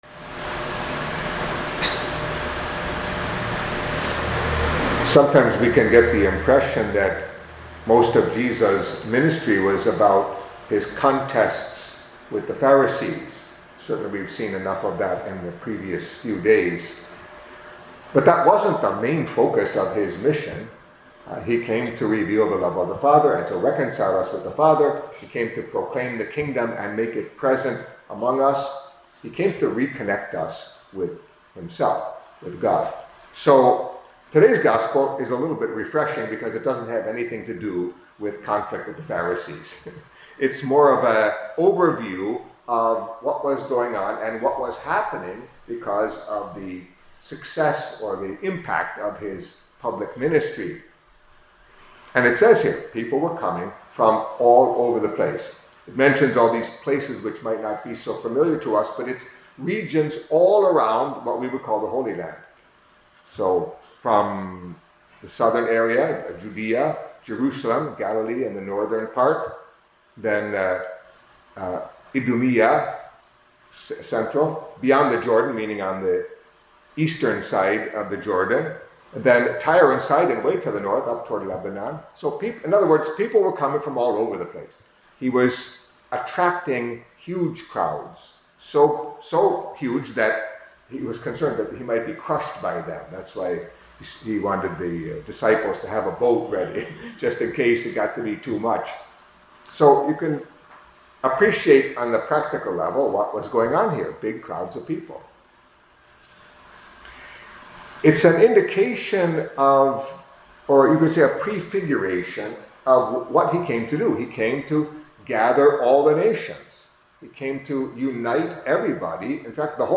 Catholic Mass homily for Thursday of the Second Week in Ordinary Time